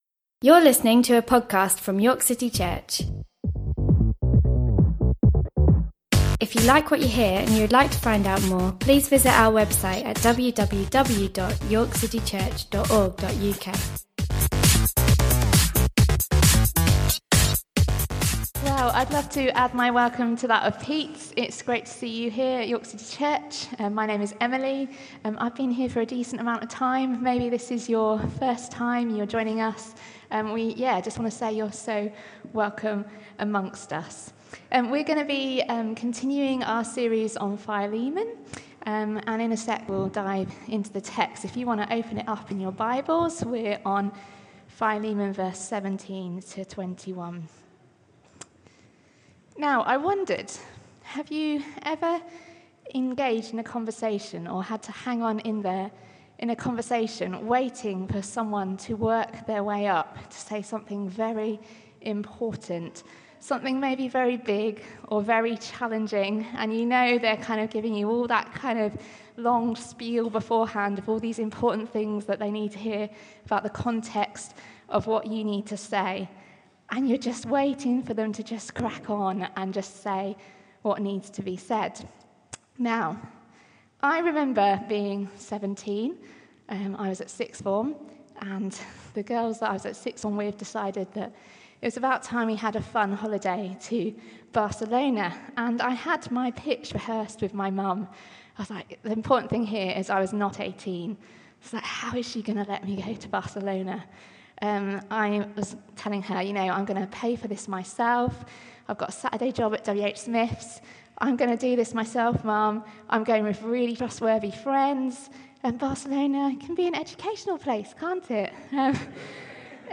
Series from the YCC preaching team on the Book of Philemon.